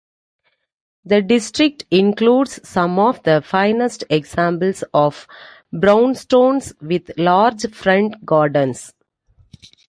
Pronúnciase como (IPA) /ɪɡˈzɑːmpl̩z/